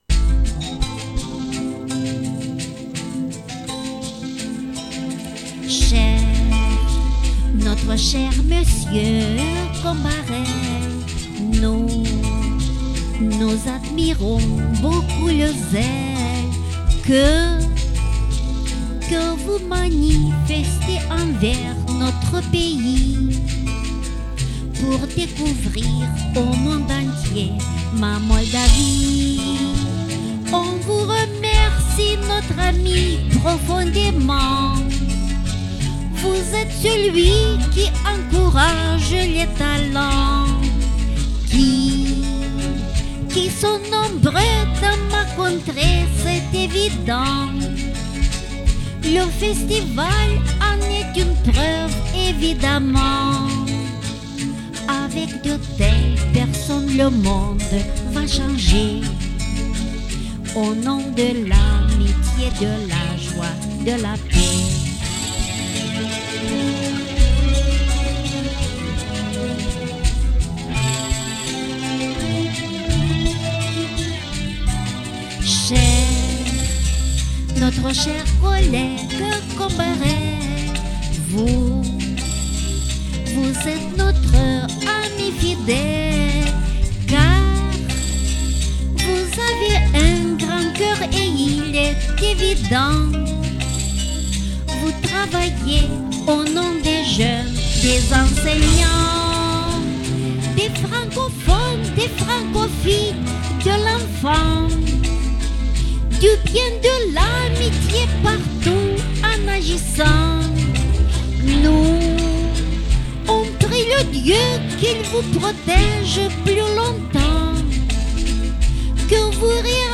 une chanson